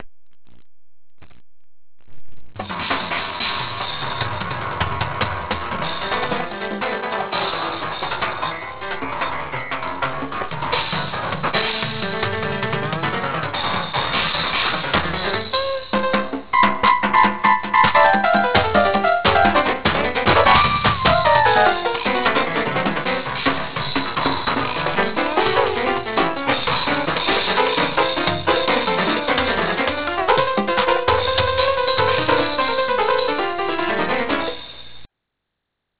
Sound sketch, like a Japanese jazz pianist...